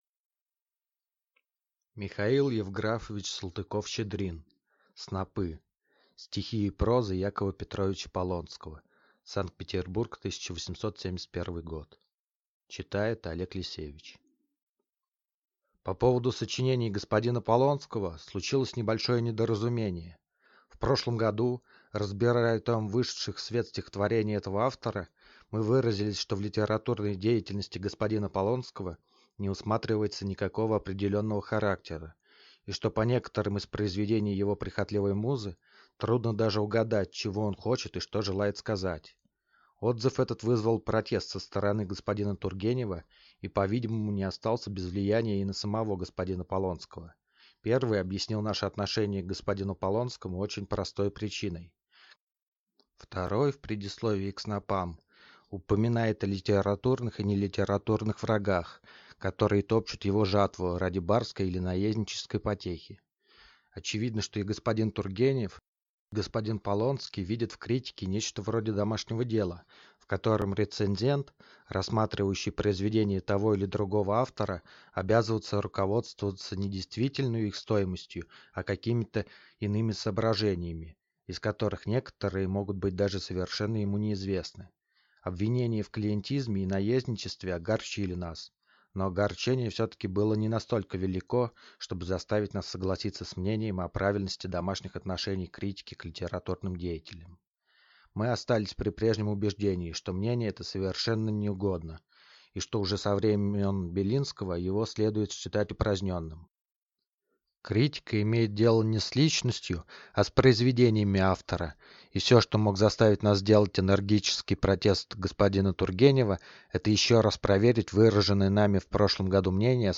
Аудиокнига Снопы | Библиотека аудиокниг